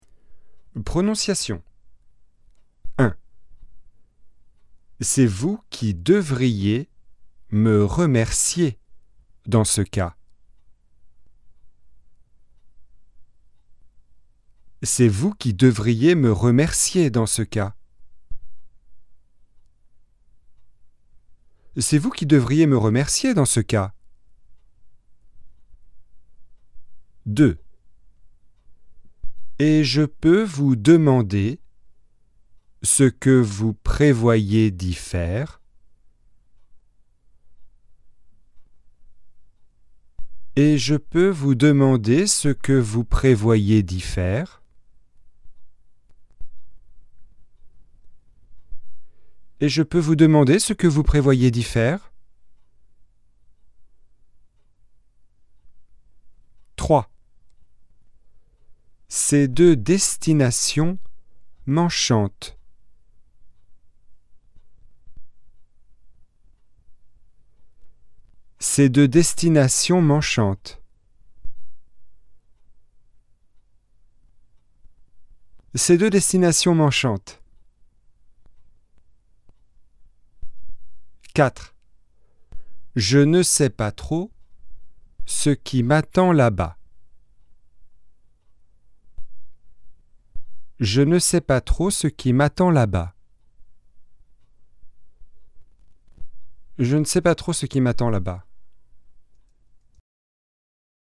🔷 Prononciation :
ami_02_prononciation.mp3